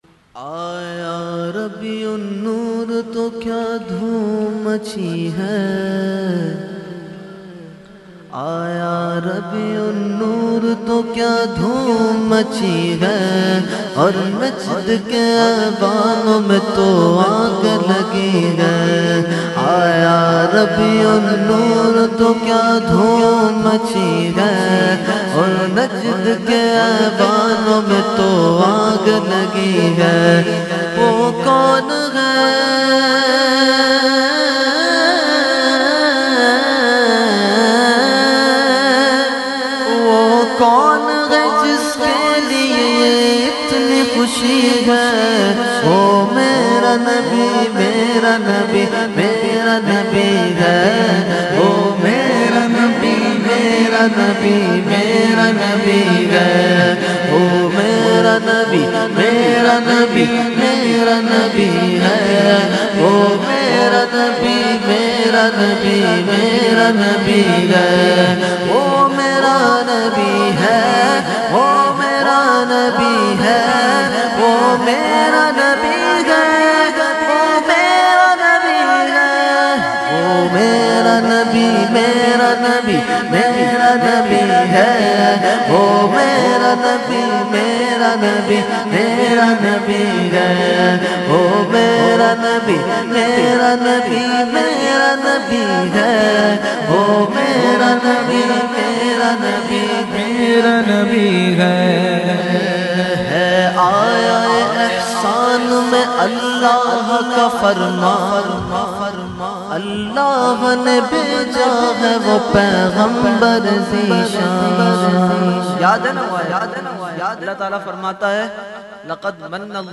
Mehfil e Meelad un Nabi ﷺ Held On 28 October 2020 At Jama Masjid Ameer Hamza Nazimabad Karachi.
Category : Naat | Language : UrduEvent : Mehfil e Milad Jamia Masjid Ameer Hamza 2020